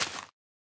Minecraft Version Minecraft Version snapshot Latest Release | Latest Snapshot snapshot / assets / minecraft / sounds / block / big_dripleaf / tilt_down1.ogg Compare With Compare With Latest Release | Latest Snapshot
tilt_down1.ogg